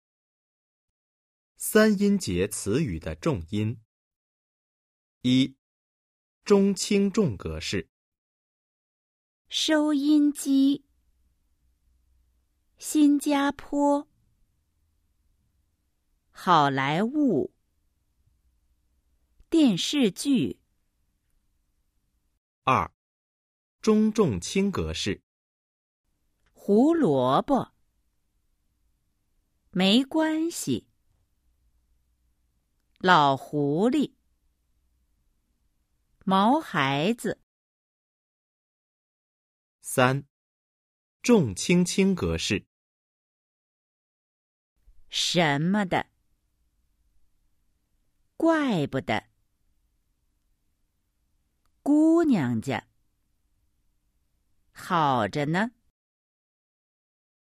■ 三音节词语的重音 Trọng âm của từ ngữ có ba âm tiết  💿 02-05
Đa số từ ngữ có ba âm tiết thuộc cấu trúc “nhấn vừa + đọc nhẹ + nhấn mạnh”, tức âm tiết thứ nhất được đọc nhấn giọng và kéo dài vừa phải, âm tiết thứ hai được phát âm nhẹ và nhanh nhất, còn âm tiết thứ ba được đọc nhấn mạnh và kéo dài nhất.